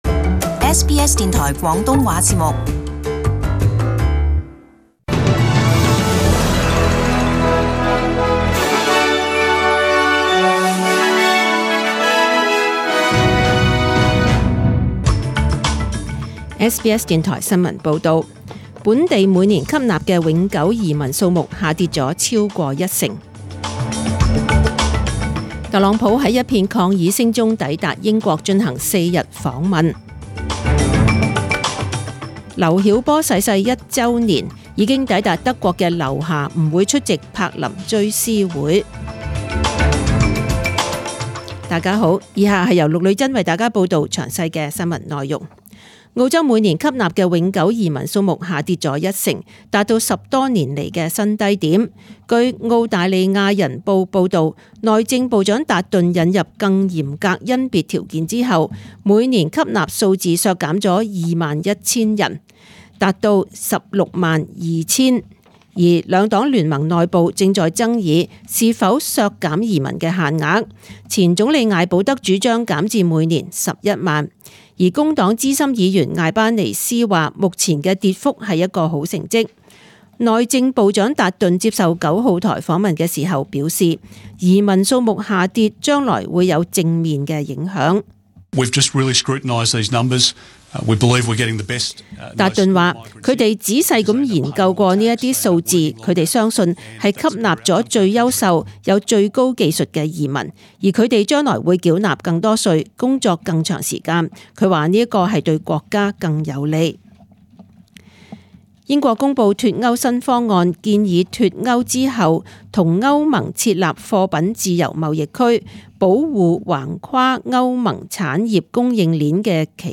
请收听本台为大家准备的详尽早晨新闻。